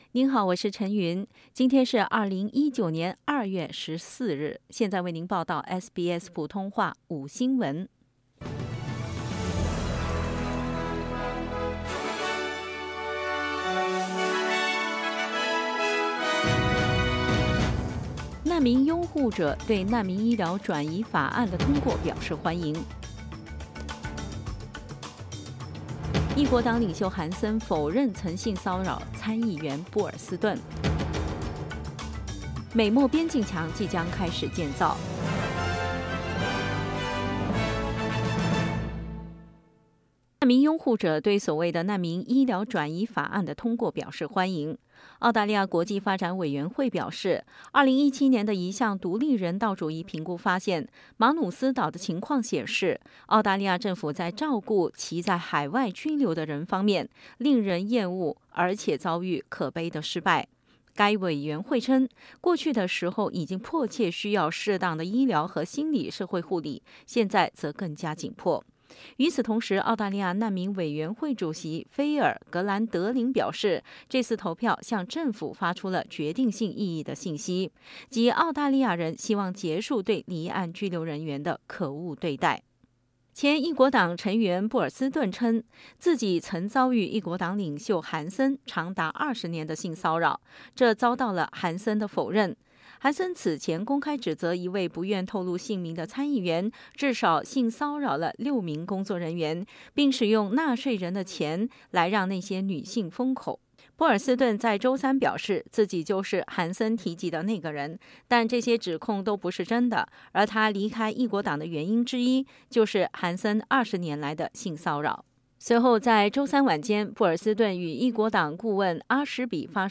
SBS午新闻 （2月14日）